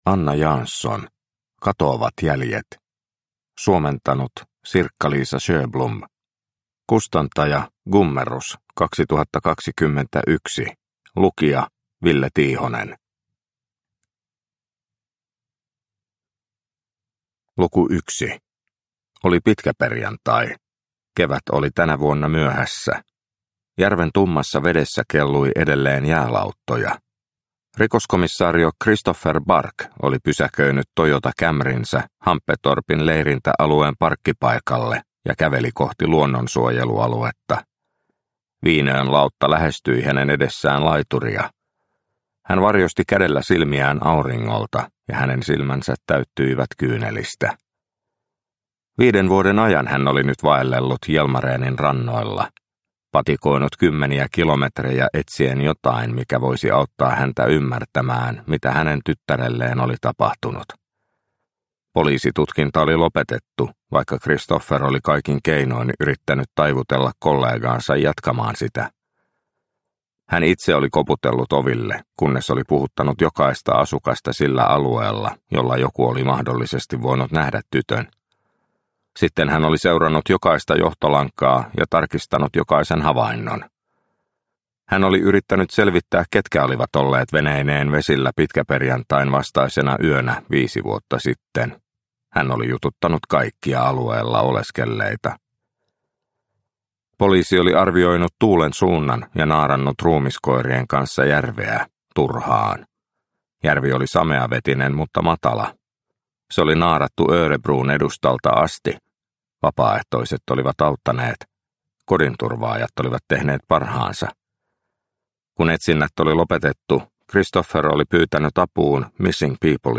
Katoavat jäljet – Ljudbok – Laddas ner